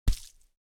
The sound Seek's eyes make when appeaing
EyeSplat.mp3